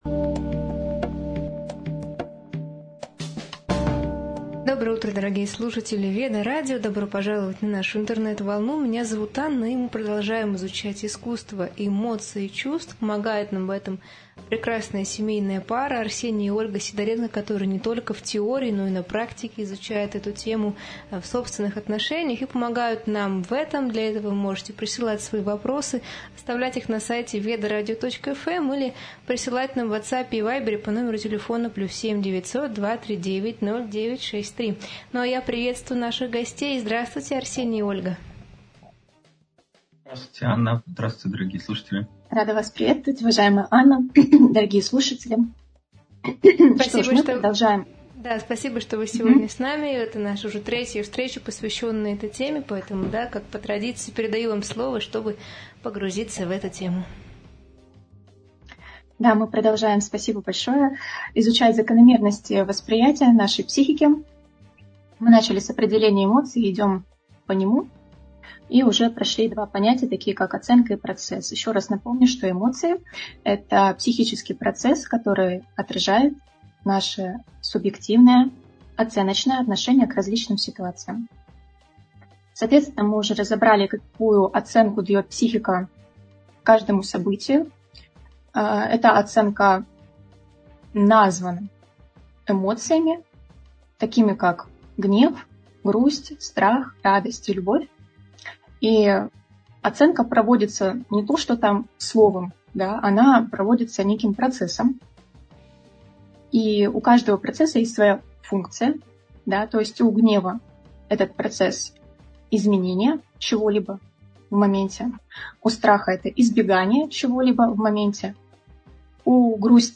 Эфир на радио Веды